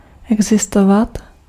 Ääntäminen
IPA: [ˈo̞lːɑˣ] IPA: /ˈol.lɑ(ʔ)/